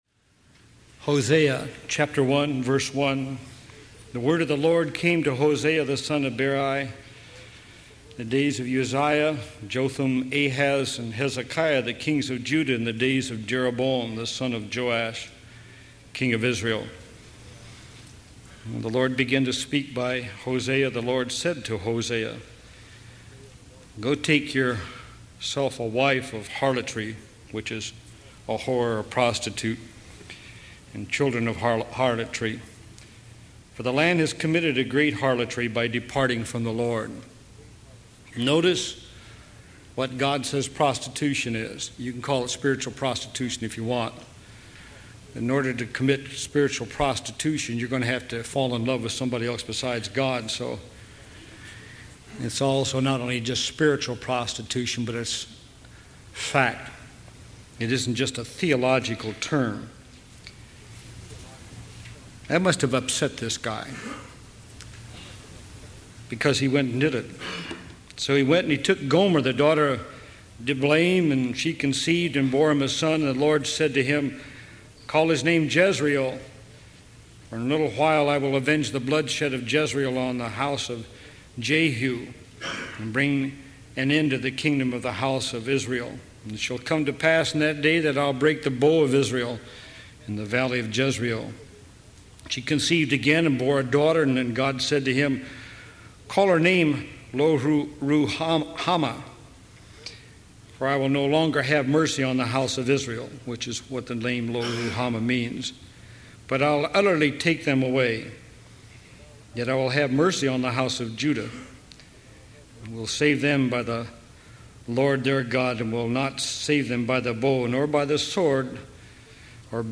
All or Nothing download sermon mp3 download sermon notes Welcome to Calvary Chapel Knoxville!